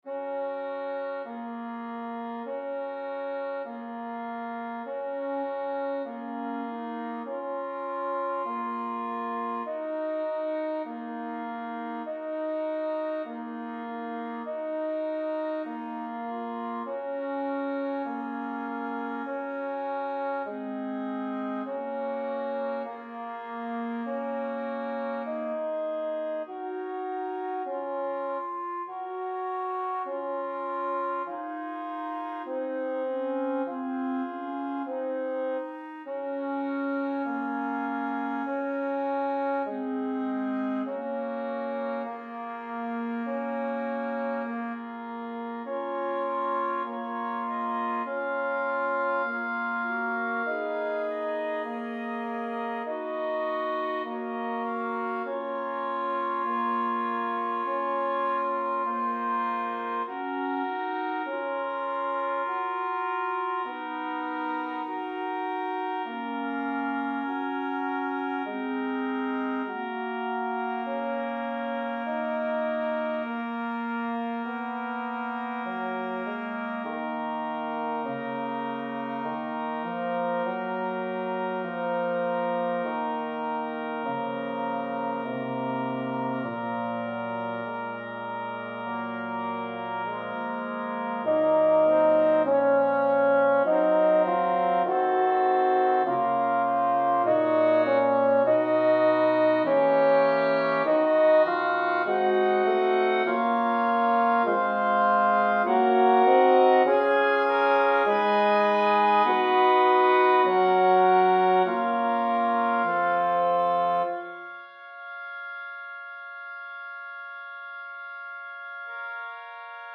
Tenor 1